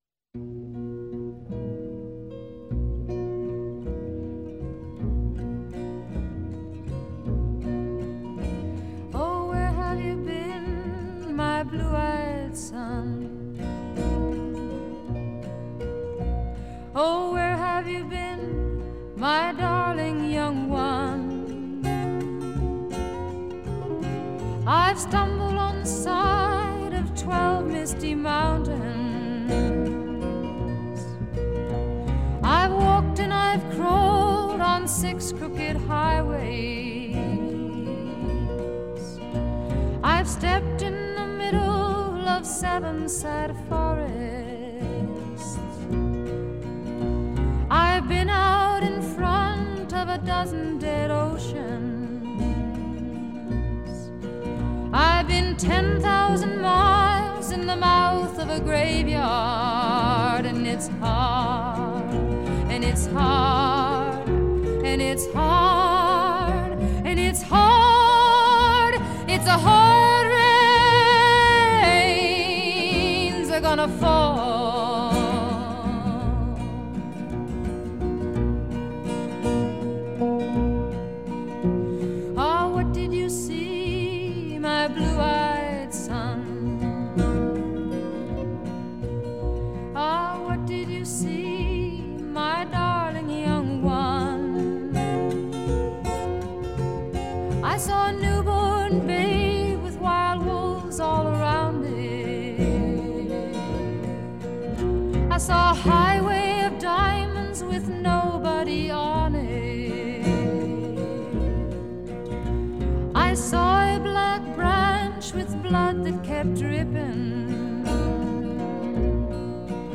撼動心弦的顫音，悠揚柔軟而充滿強韌勁道的高音演唱